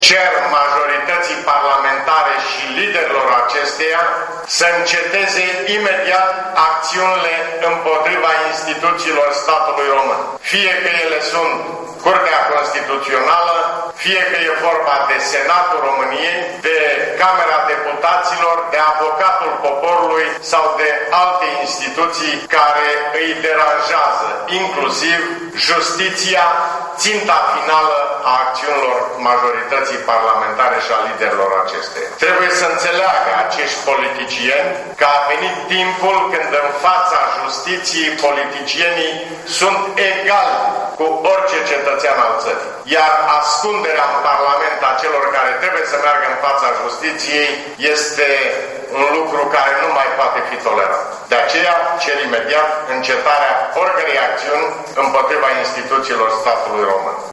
Fragment din declarația preşedintelui Traian Băsescu